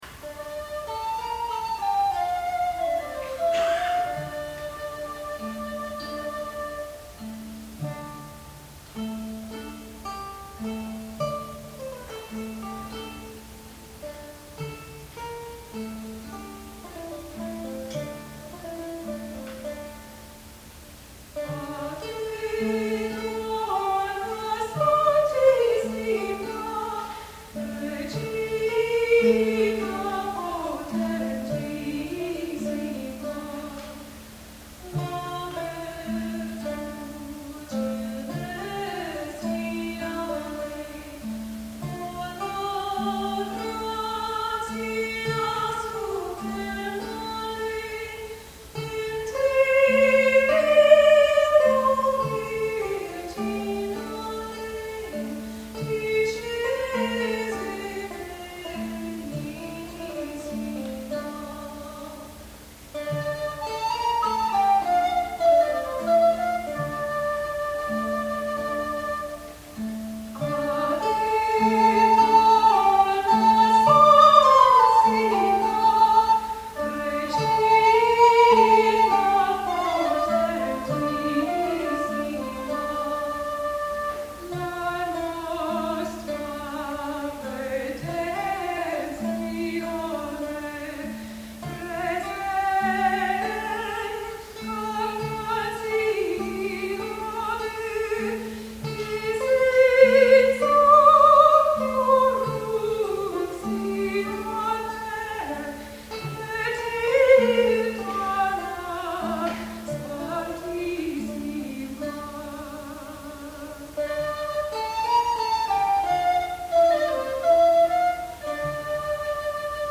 This beautiful lauda is one of 46 found in a 13th century ms. at Cortona, Italy.  All are monophonic with no rhythmic notations . . . a simple harmony and rhythm have been applied for this performance.
sopramo